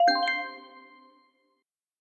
Notification_H.ogg